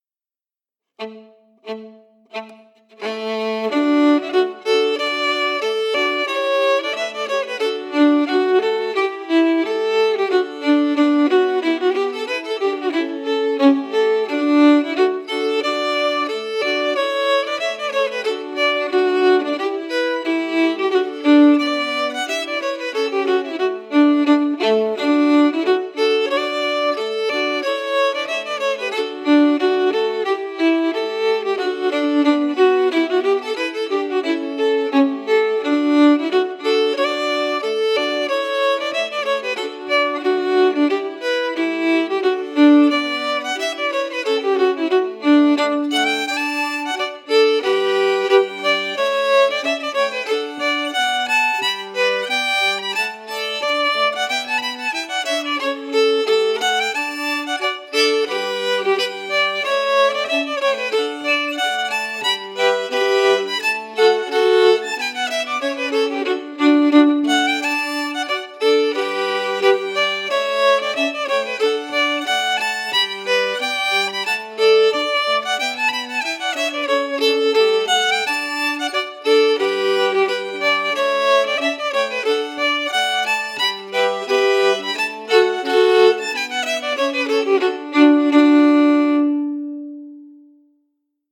Key: D
Form: March
Melody emphasis
Region: Shetland